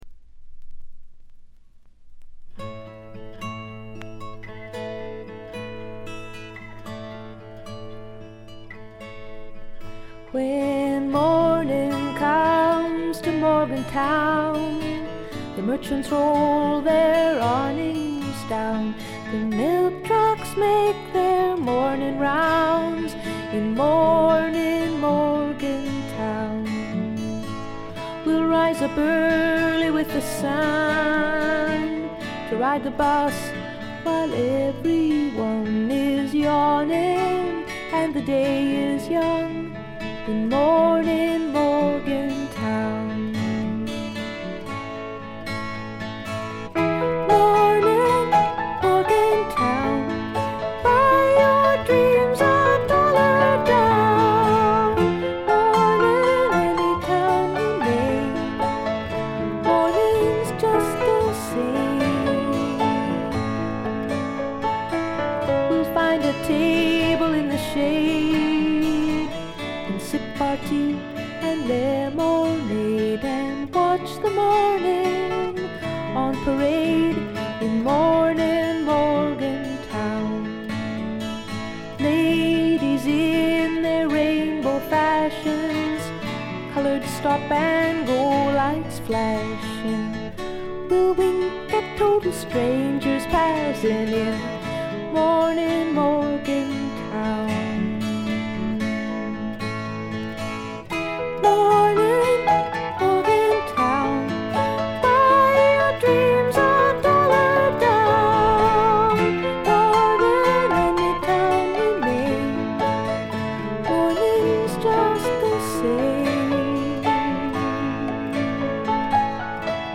ところどころで軽微なチリプチ。
美しいことこの上ない女性シンガー・ソングライター名作。
試聴曲は現品からの取り込み音源です。
Recorded At - A&M Studios